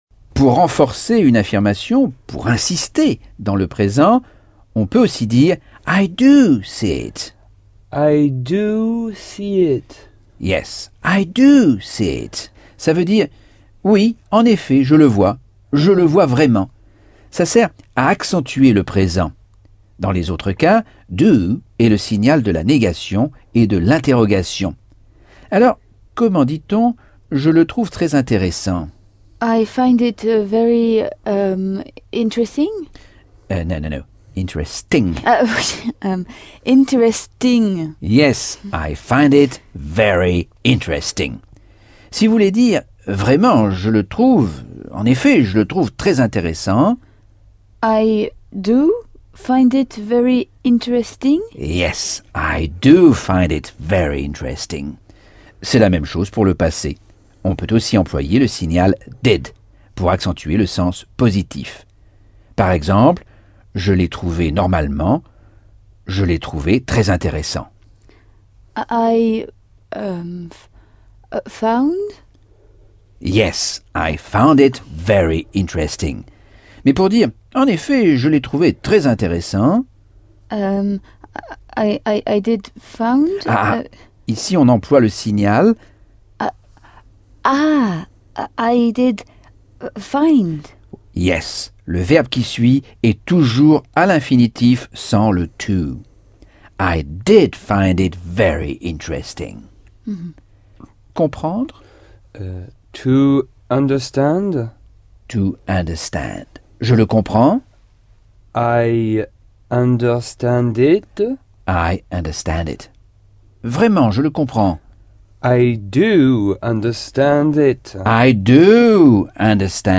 Leçon 10 - Cours audio Anglais par Michel Thomas - Chapitre 10